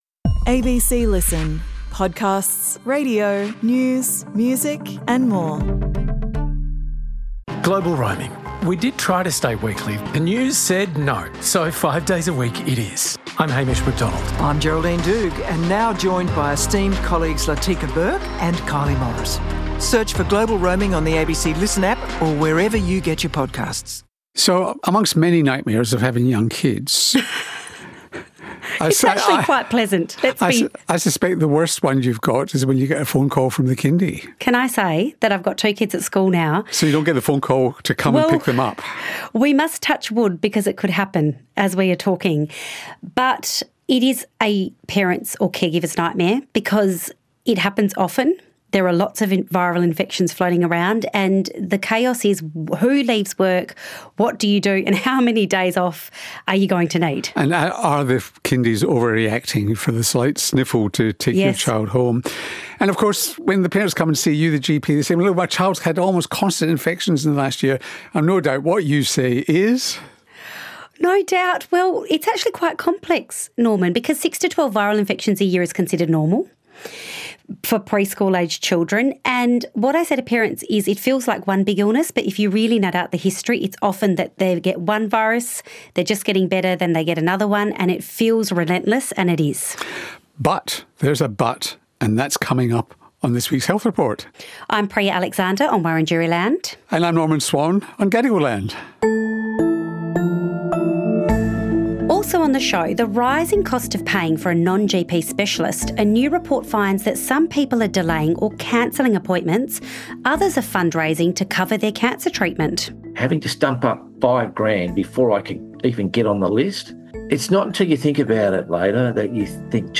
Download - New Australian guidelines for cardio vascular disease | Phone-connected device can collect heart rhythm data | Panel discussion on reducing red and processed meat consumption | Podbean